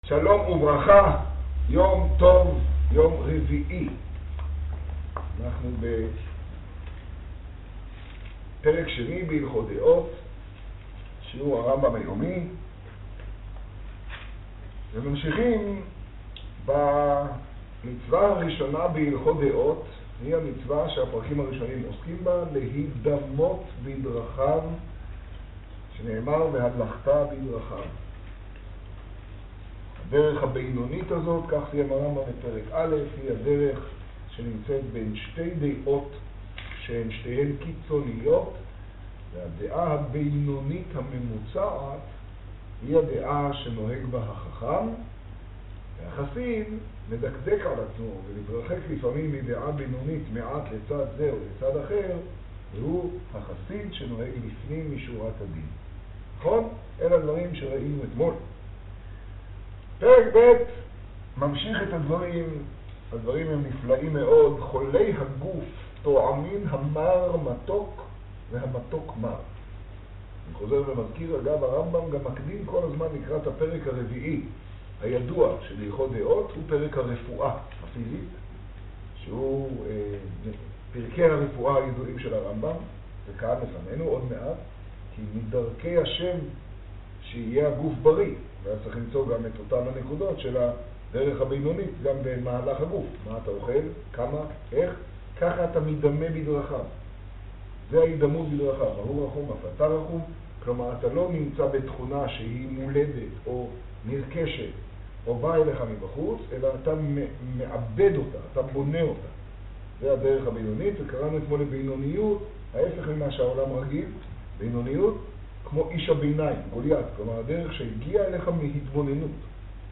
השיעור במגדל, טז טבת תשעה.